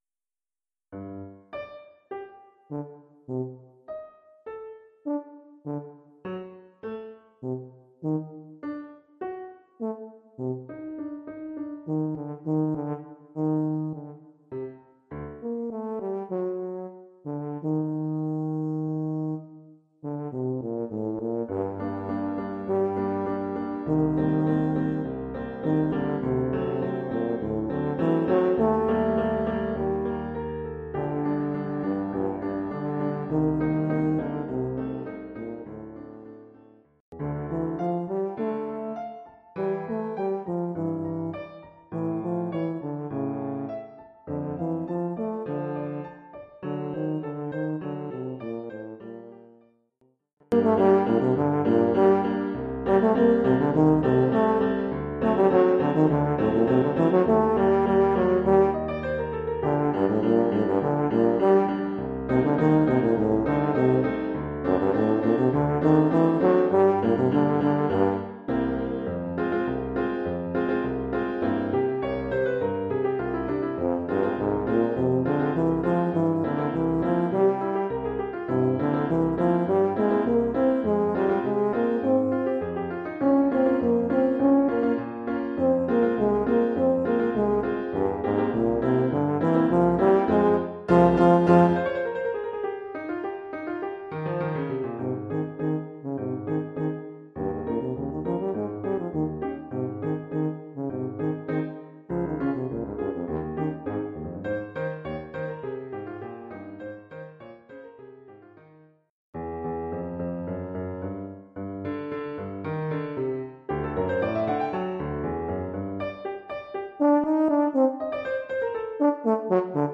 Oeuvre pour saxhorn basse / euphonium / tuba,
avec accompagnement de piano.